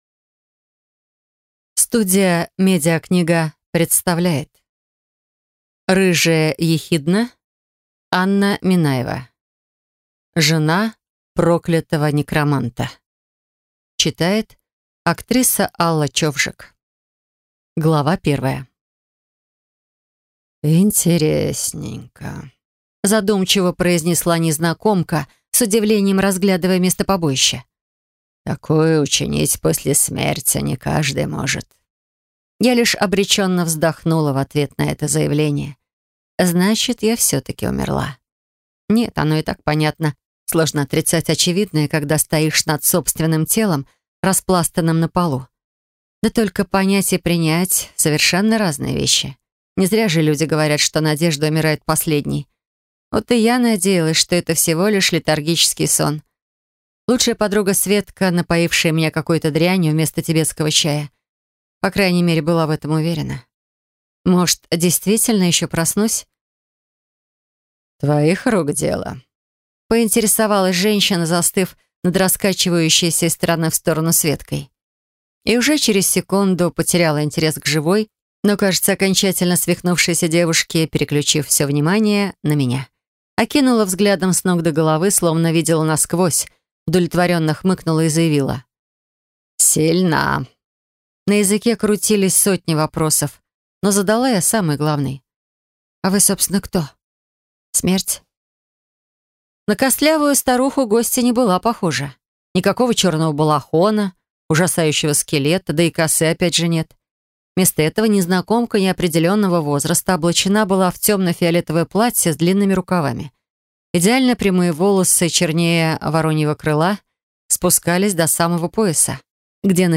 Аудиокнига Жена проклятого некроманта | Библиотека аудиокниг
Прослушать и бесплатно скачать фрагмент аудиокниги